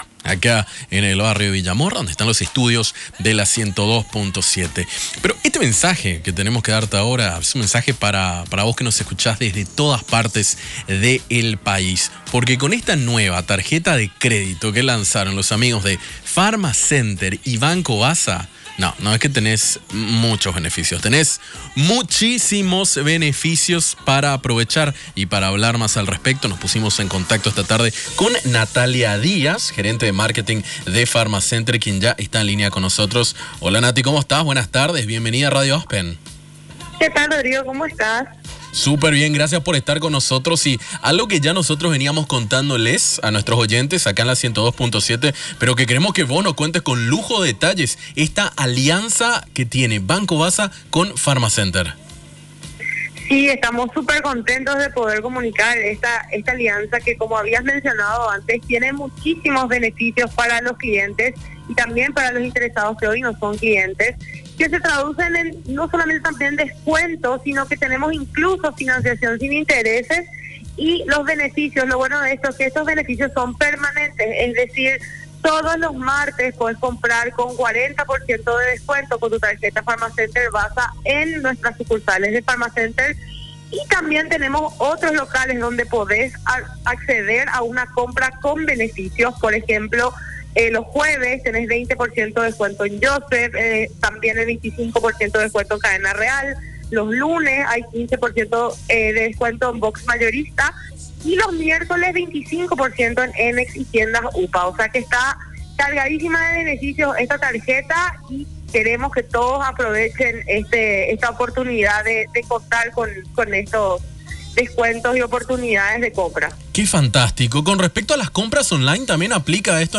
ENTREVISTA-BANCO-BASA-ASPEN.mp3